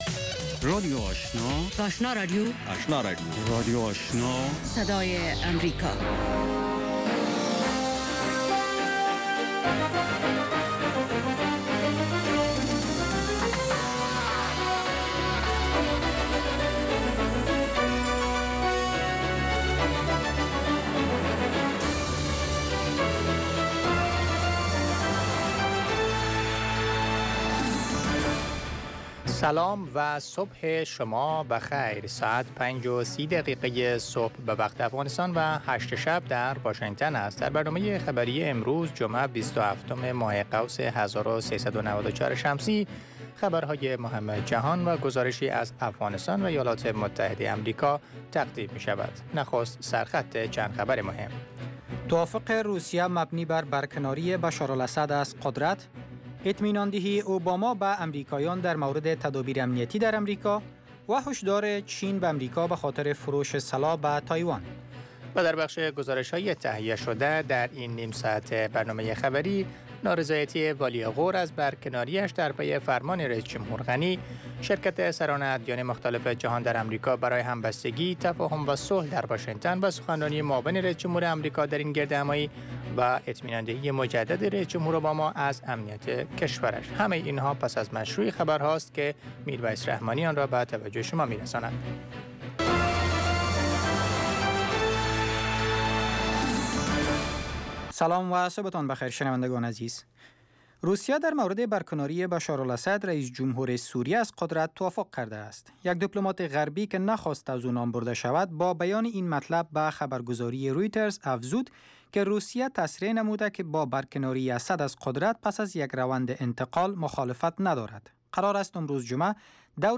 اولین برنامه خبری صبح